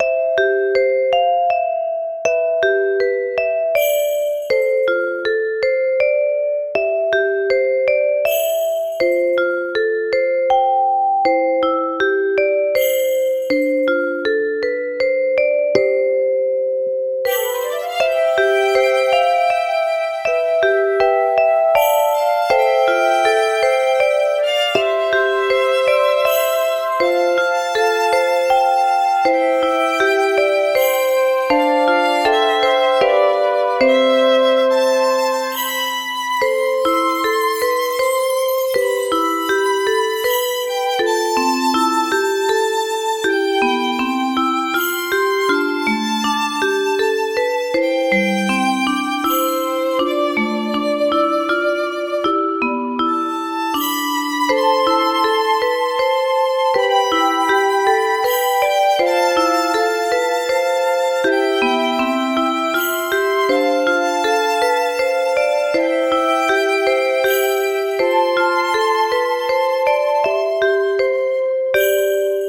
ゆったりした楽曲
【イメージ】感動、切ない、オルゴール など